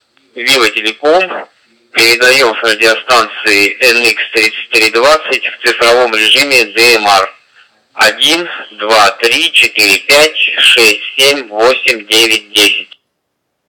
Пример модуляции (передачи) радиостанций серии NX-3000 в цифровом режиме DMR:
nx-3000-tx-dmr.wav